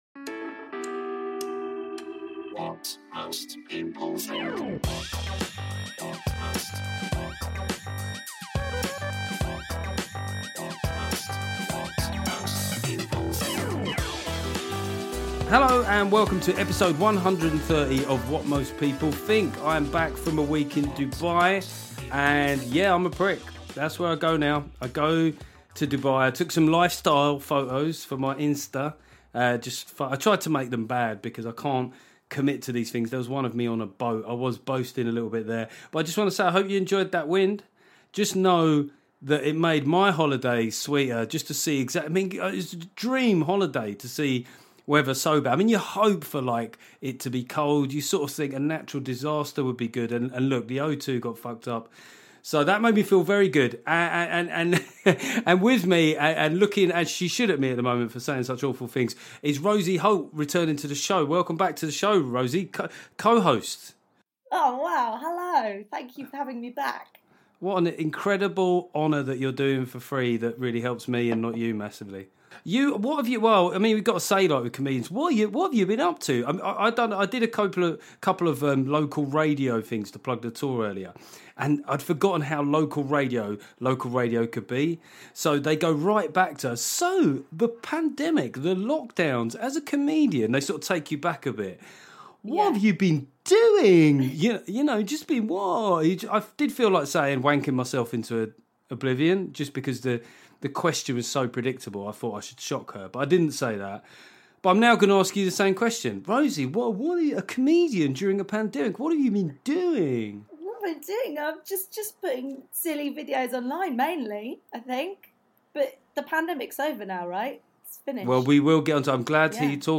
This week viral sensation Rosie Holt is back to co-host.